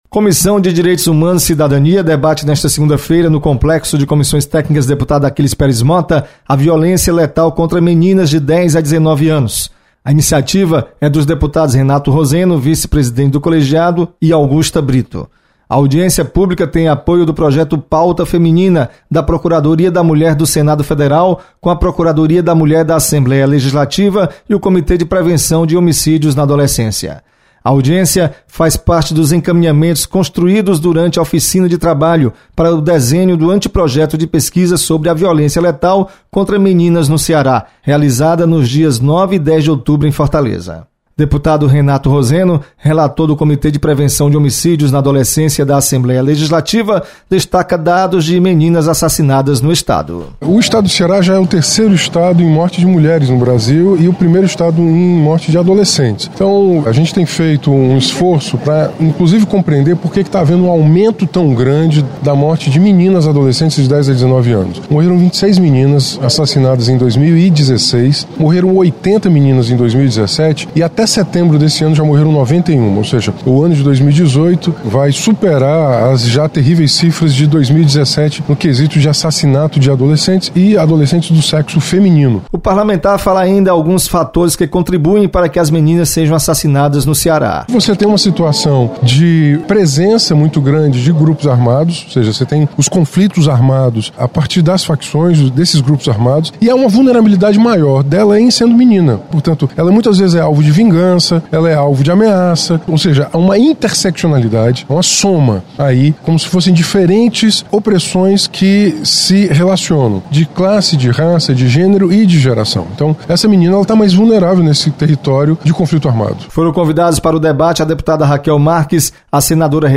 Deputado Renato Roseno comanda audiência sobre prevenção de homicídios na Adolescência. Repórter